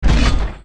academic_skill_spannersmashing.ogg